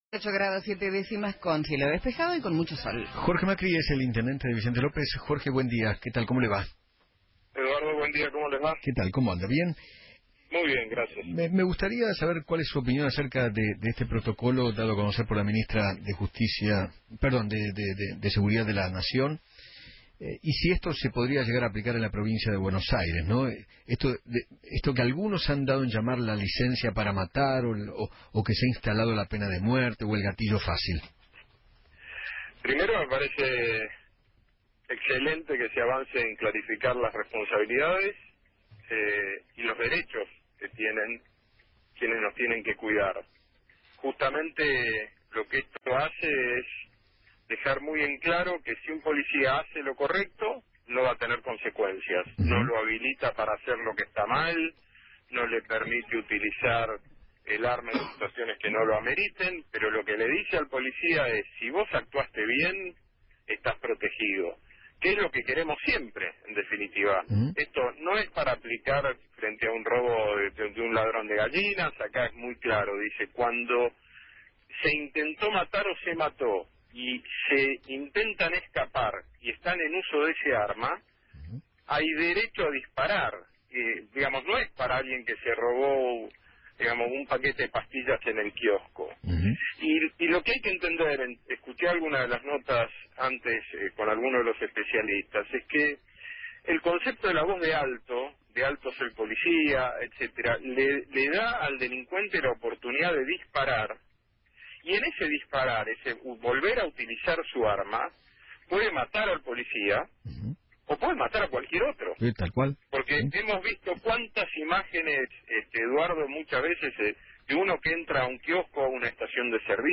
Jorge Macri, Intendente de Vicente López, habló en Feinmann 910 y dijo que “Me parece excelente que se avance en clarificar las responsabilidades y los derechos de quienes nos tienen que cuidar; lo que hace el cambio de reglamento es dejar bien claro que si un policía hace lo correcto, no va a tener consecuencias, no le permite hacer lo que está mal o usar el arma en situaciones que no ameriten.